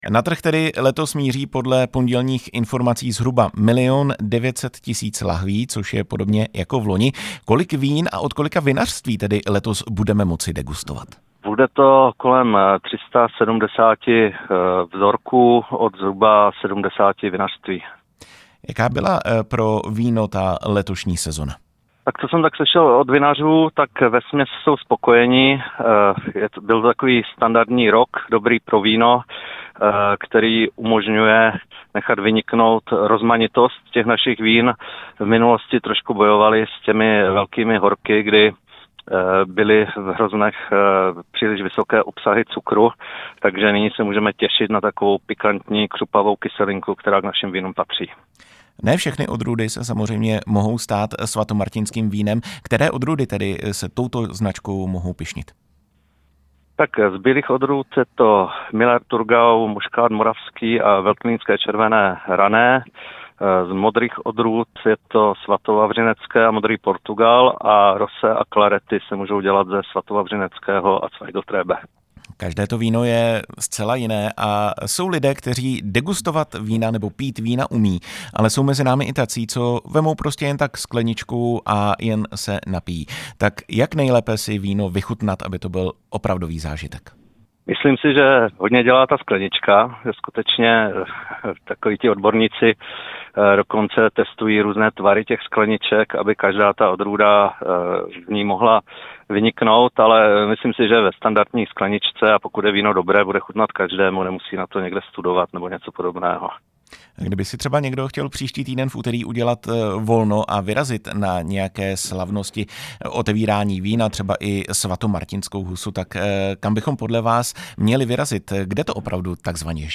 Rozhovory